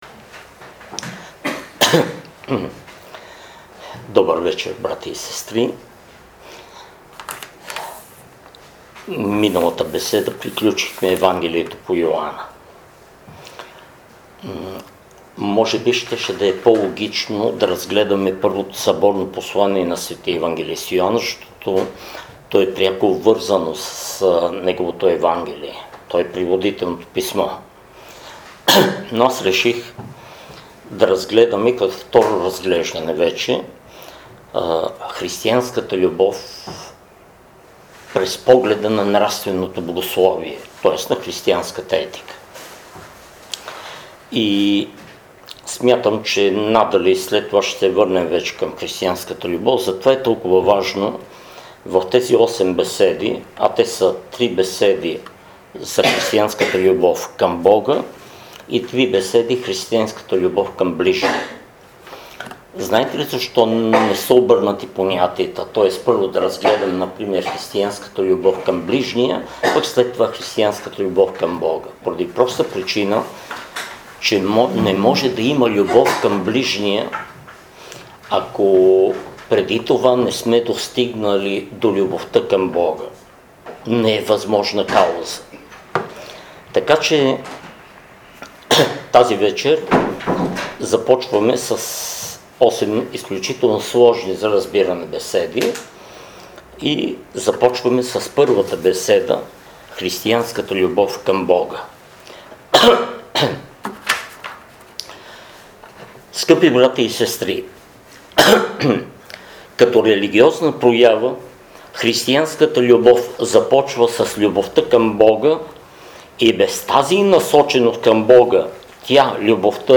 Християнската любов към Бога и ближните втора беседа